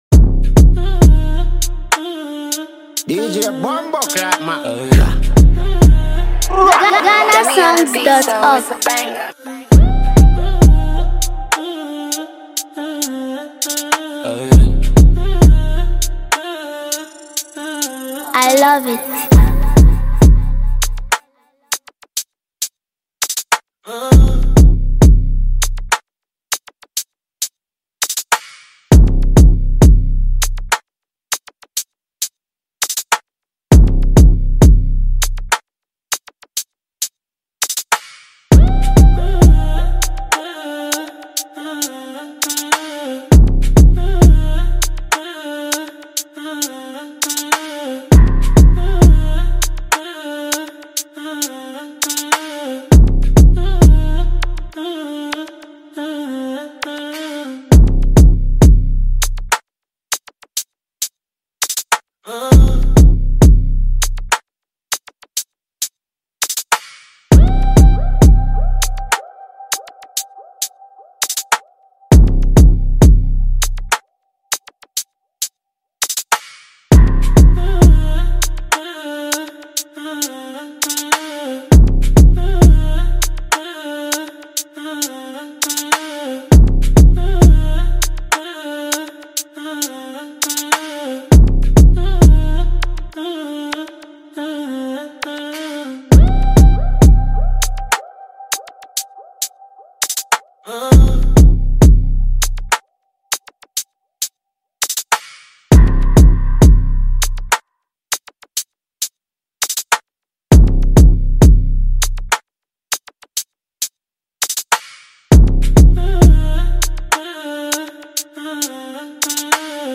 clean mixing, strong sound balance, and a modern feel